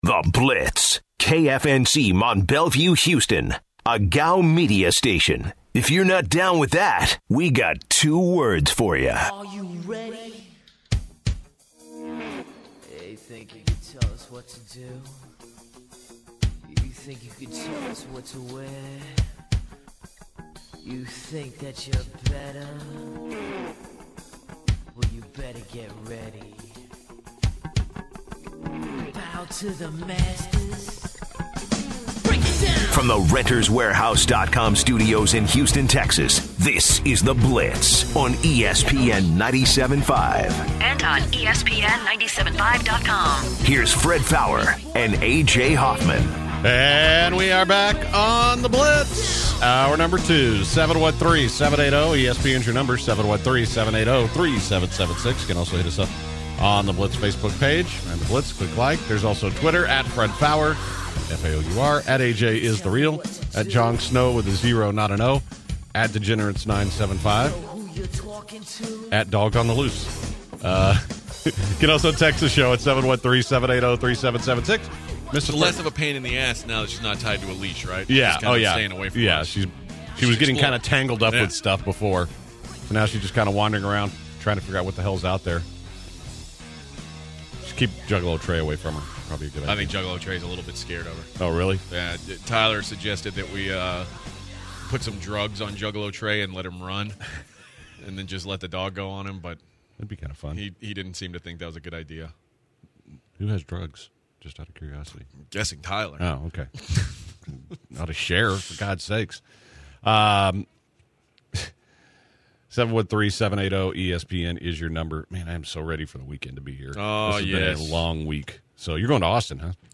also calls in for an exclusive interview.